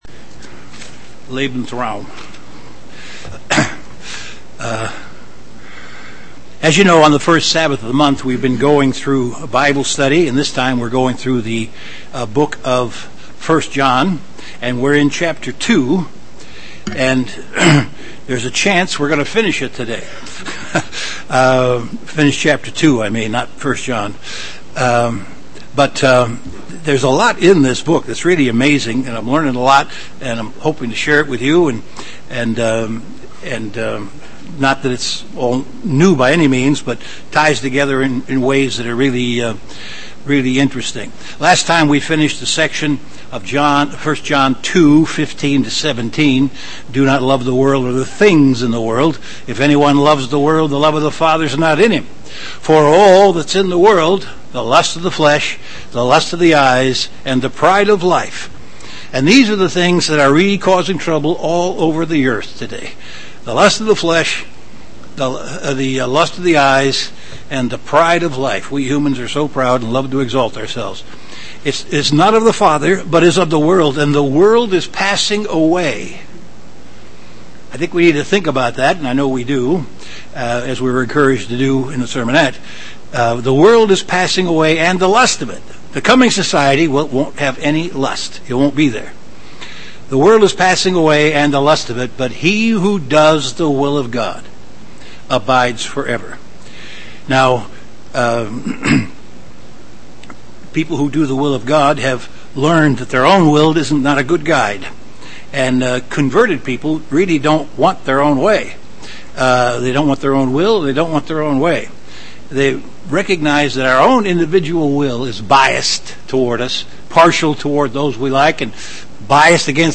The third in a three part series of an in-depth Bible study on the book of 1 John chapter 2.
Given in Chicago, IL
UCG Sermon Studying the bible?